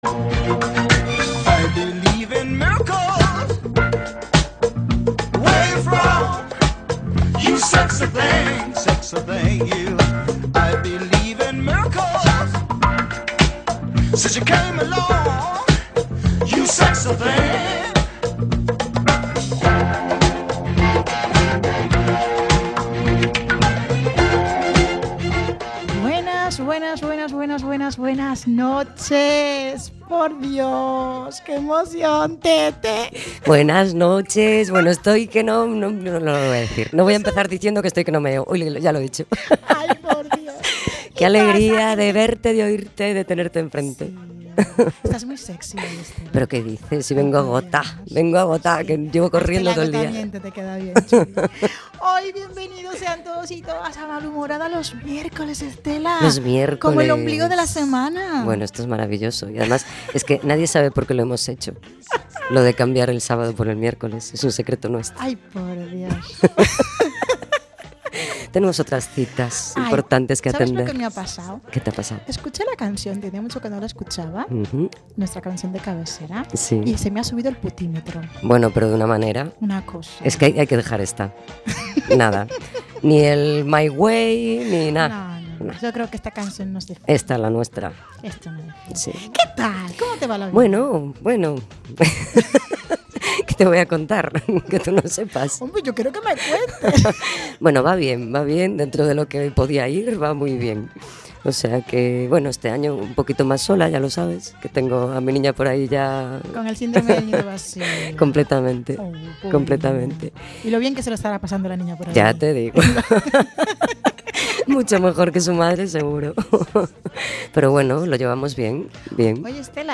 Somos un par de malhumorhadas que, en cada programa, creceremos en número y en espíritu, acompañándonos de personas que, como nosotras, se pre-ocupan y se ocupan de las cosas importantes, dando apoyo a proyectos y movimientos sociales con diferentes causas y objetivos. Todo esto con mucho humor (bueno y malo) y con mucha música y diversión, todos los miércoles a las 20:00 en Cuac FM (redifusión: sábados 10:00) y los jueves a las 14:00 en OMC Radio.